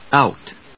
out /άʊt/